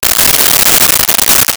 Plastic Wrap 03
Plastic Wrap 03.wav